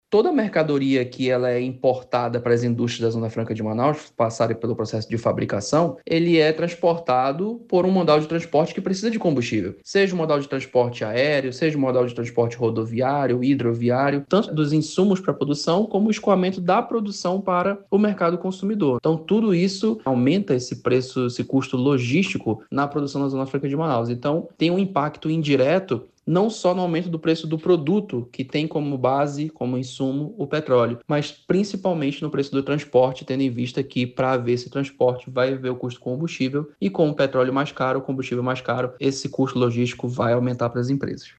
No Amazonas, o Polo Industrial de Manaus – PIM começa a sentir os impactos da guerra no Oriente Médio, ressalta o economista.